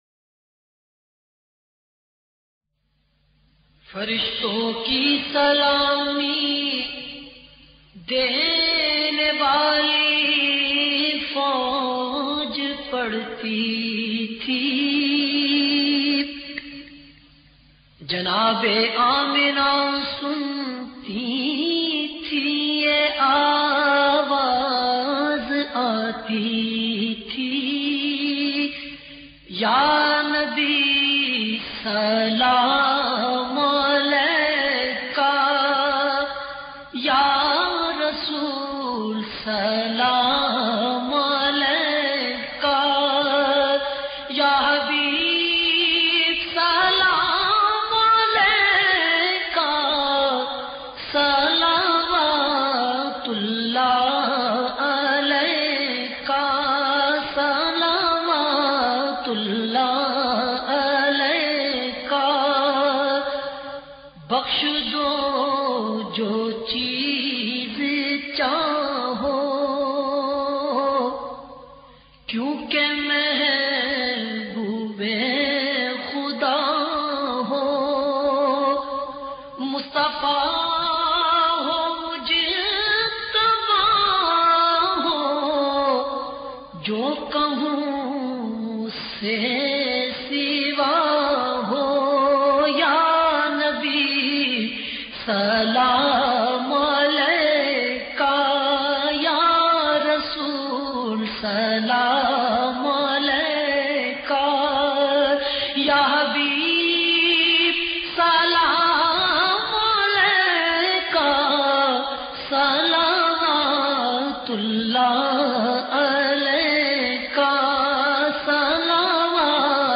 Beautifull Naat
in best audio quality